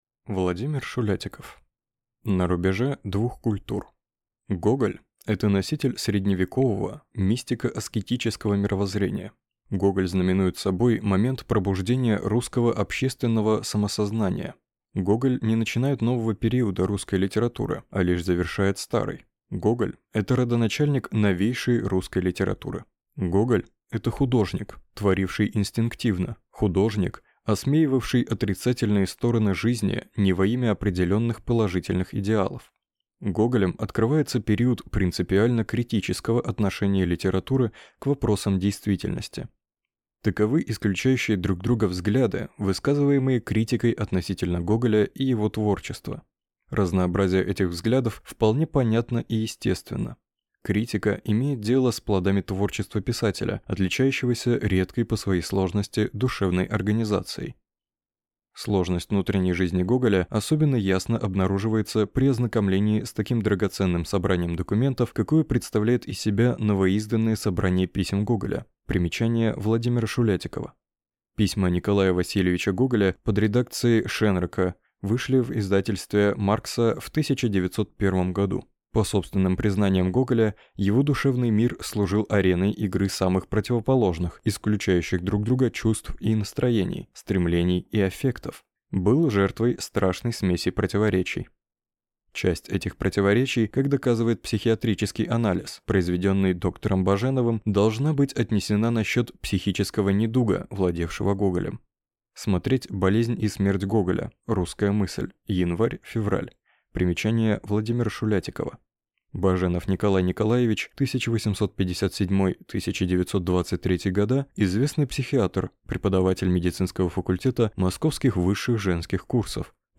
Аудиокнига На рубеже двух культур | Библиотека аудиокниг